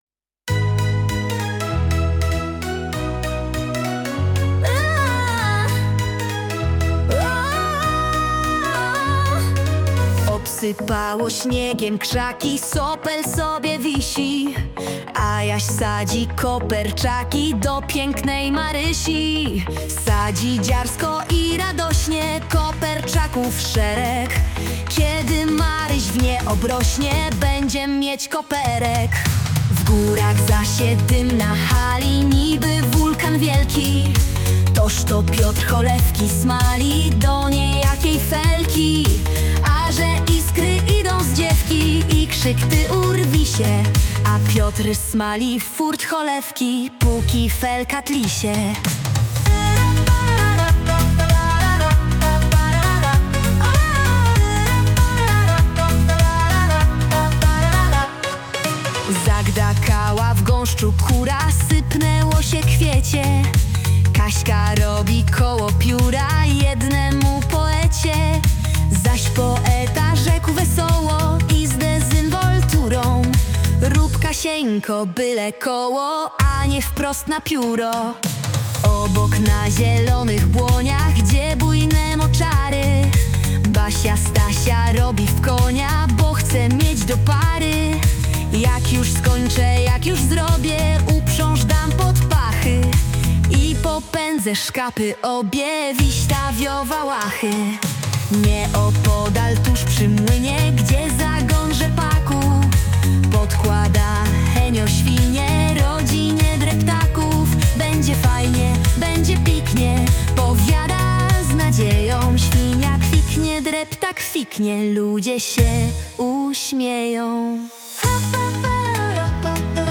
(cover) Klasyka w nowej odsłonie.